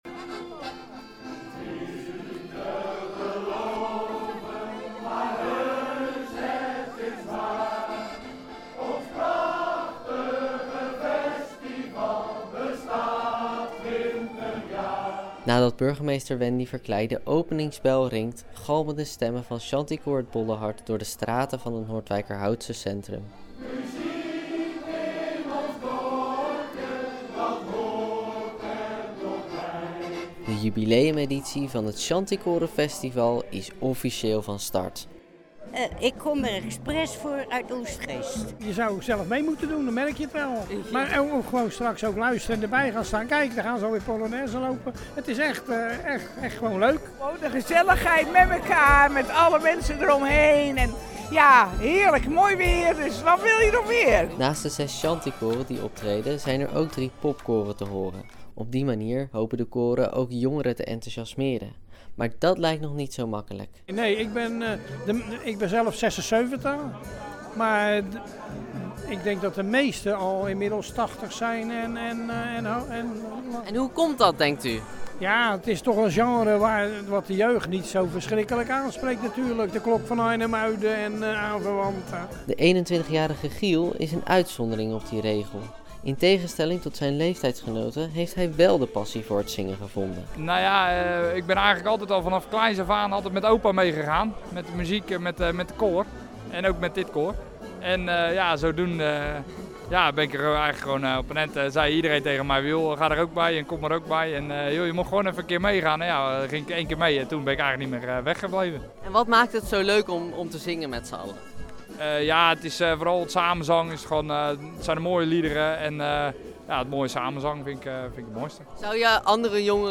Noordwijkerhout – ‘T is niet te geloven, maar heus ‘t is waar: zaterdag 17 augustus vond de 20ste editie van het Shantykorenfestival plaats in het centrum van Noordwijkerhout. Zes shantykoren en drie popkoren betraden de drie podia in het centrum.
Shantykoor_mixdown.mp3